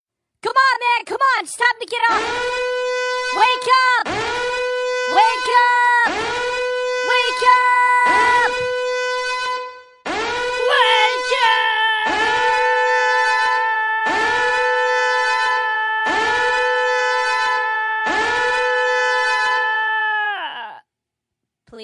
Annoying Alarm